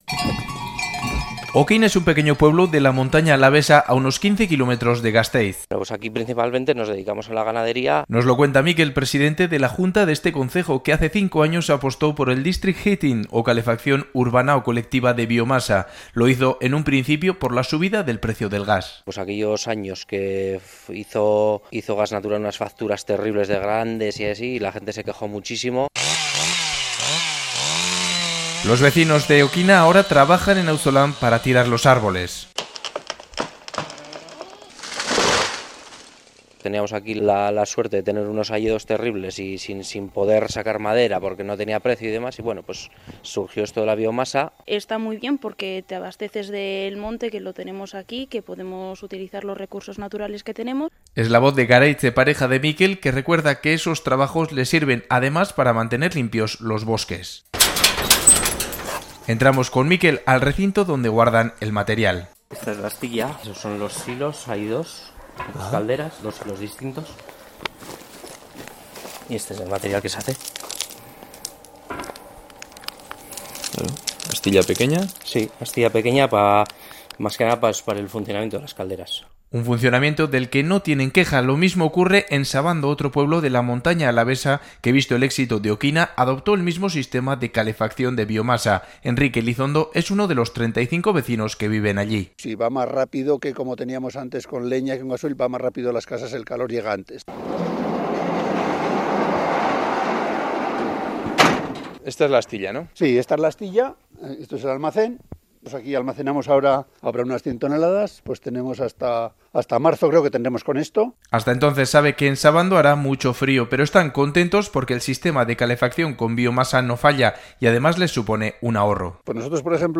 Radio Euskadi REPORTAJES